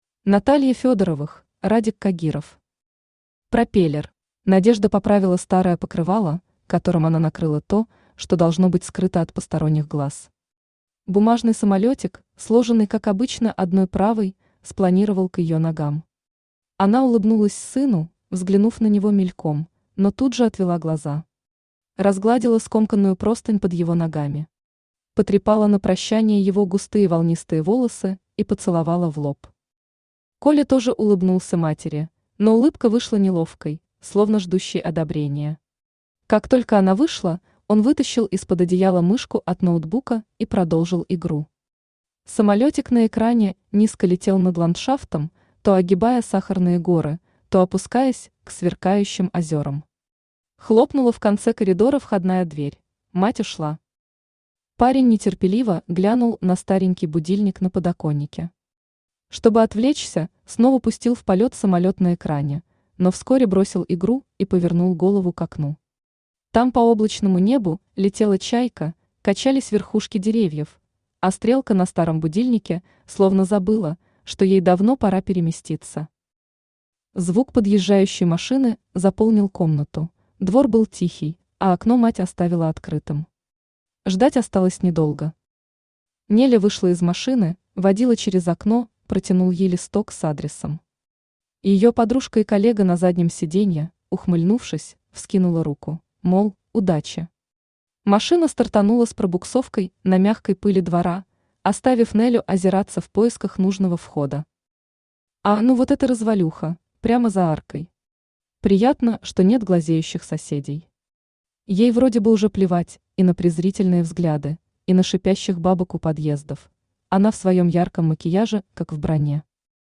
Аудиокнига Пропеллер | Библиотека аудиокниг
Aудиокнига Пропеллер Автор Радик Кагиров Читает аудиокнигу Авточтец ЛитРес.